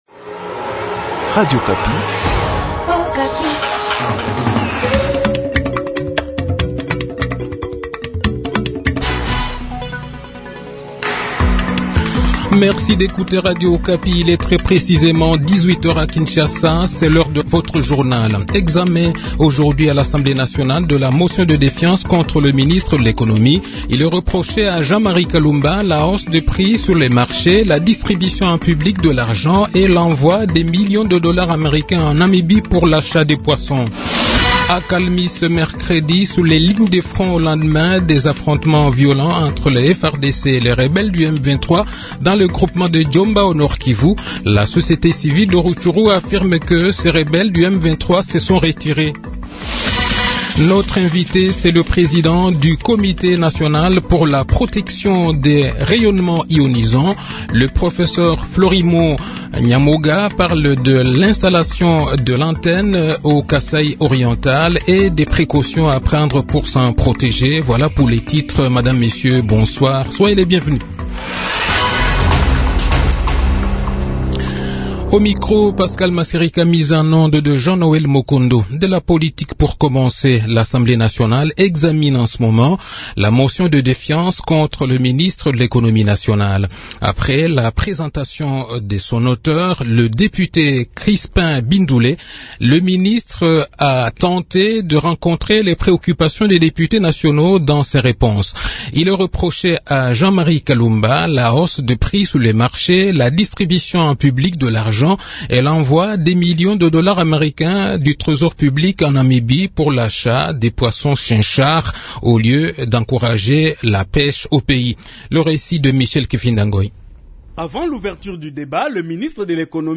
Le journal de 18 h, 30 mars 2022